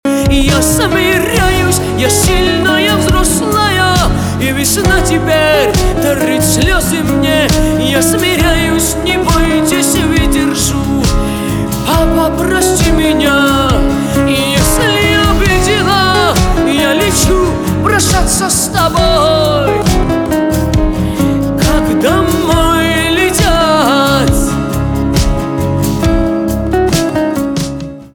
поп
барабаны , гитара , грустные
чувственные